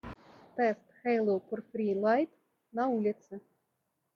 Двойной микрофон с шумоподавлением cVc во время звонков работает хорошо.
В шумных условиях:
haylou-purfree-lite-v-shumnyh-uslovijah.mp3